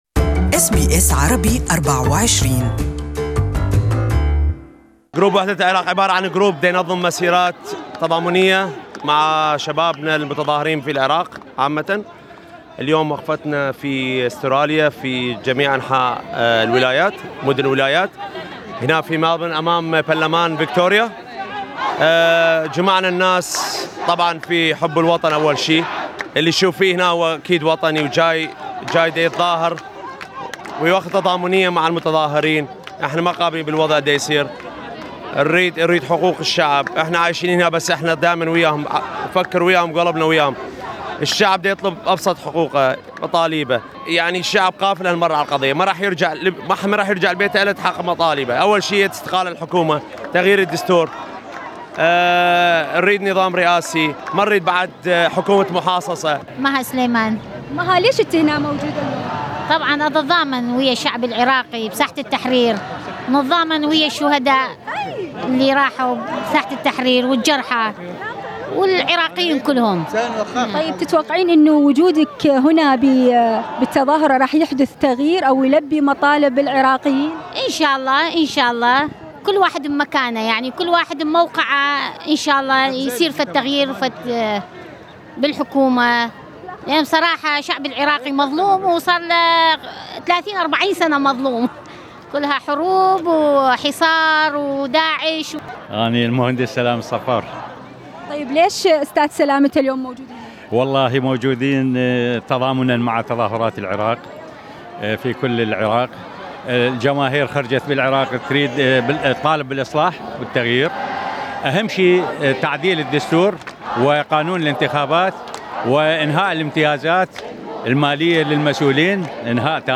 Iraqi Demonstration in Melbourne Source: MA